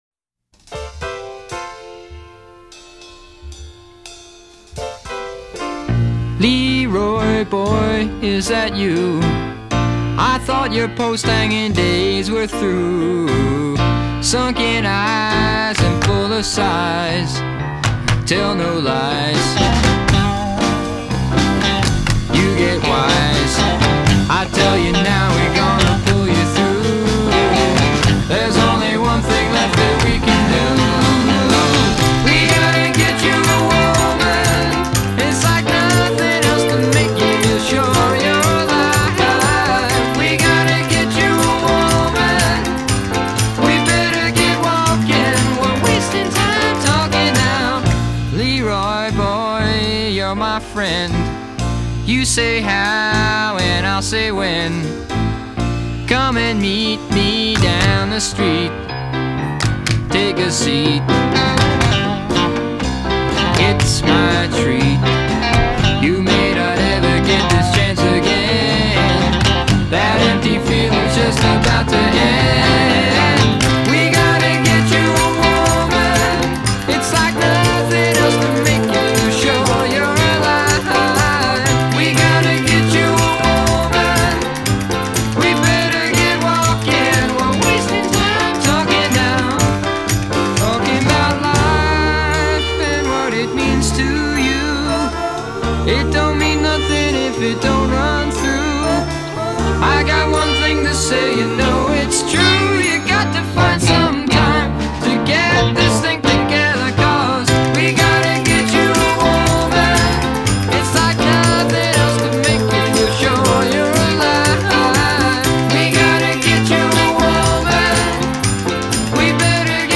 including keyboards and guitars.